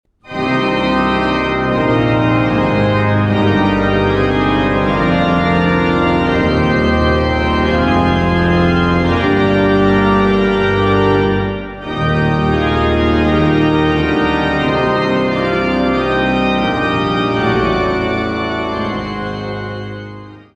Walcker-Orgel St. Jakobus zu Ilmenau